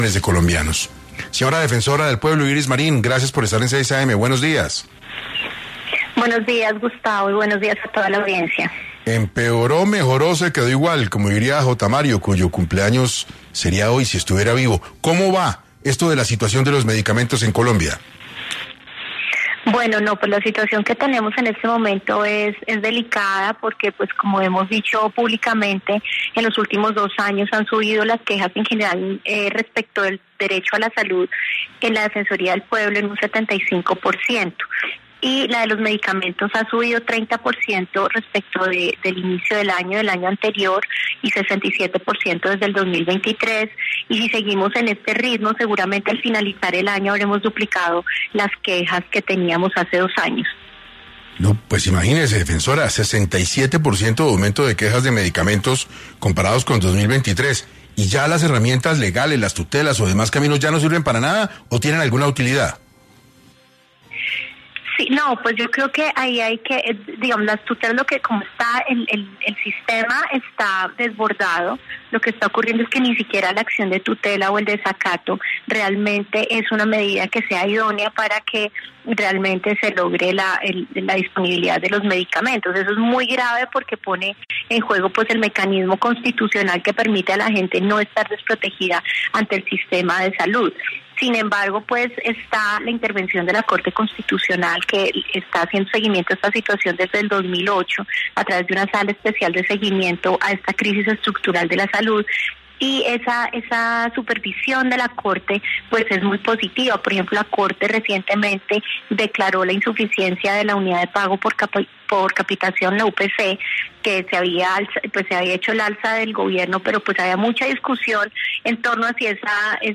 Iris Marín, defensora del Pueblo, explicó hoy para 6AM, qué está pasando con escasez de medicamentos y cuáles podrían ser las soluciones a la crisis del sistema de salud